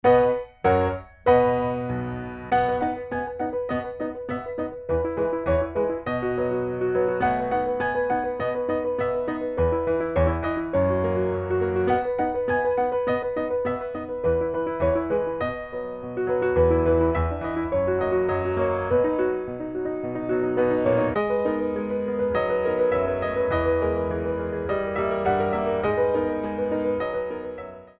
Petite Allegro
4/4 - 128 with repeat